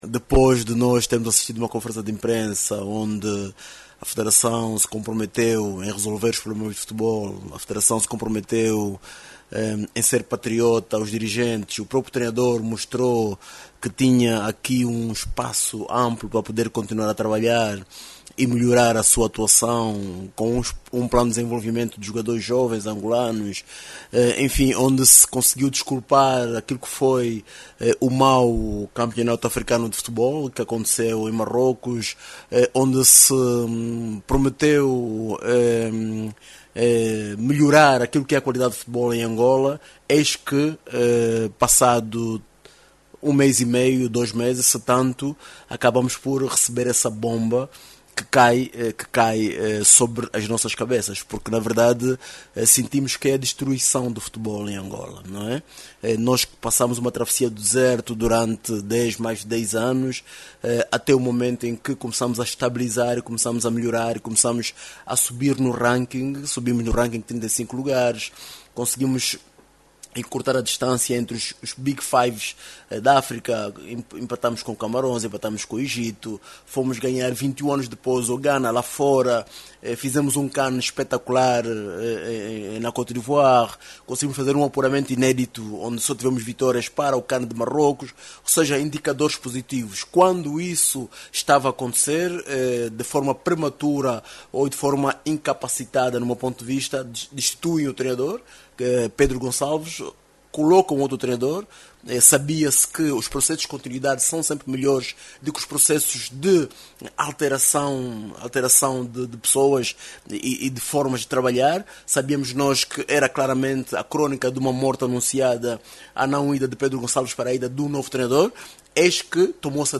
O Carnaval na província do Huambo, será marcado pela raiz cultural da região do planalto central de Angola. A maior festa popular tradicional da região tem sido evidenciada todos os anos pelos hábitos e costumes que identificam a província. Ouça o desenvolvimento desta matéria na voz do jornalista